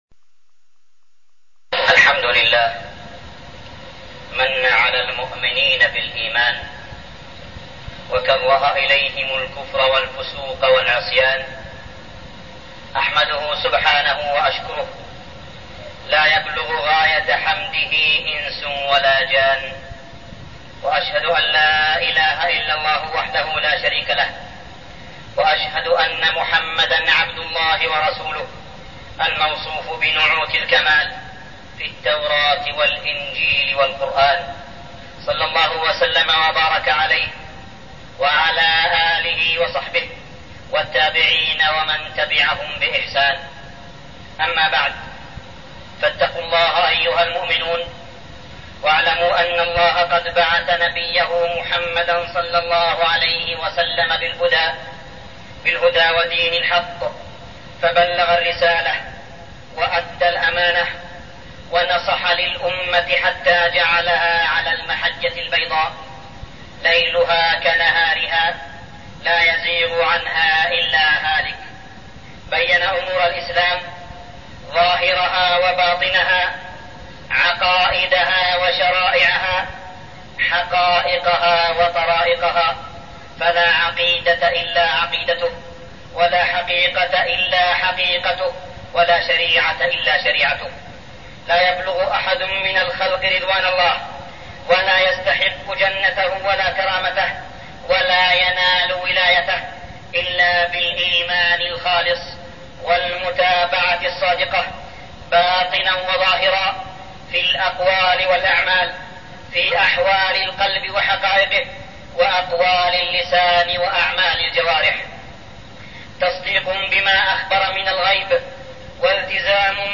تاريخ النشر ٤ ربيع الأول ١٤٠٩ هـ المكان: المسجد الحرام الشيخ: معالي الشيخ أ.د. صالح بن عبدالله بن حميد معالي الشيخ أ.د. صالح بن عبدالله بن حميد عقيدتنا في النصر والهزيمة